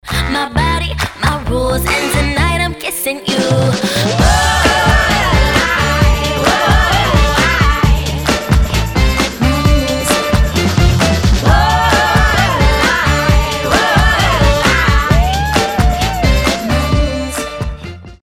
• Качество: 320, Stereo
поп
красивые
женский вокал
заводные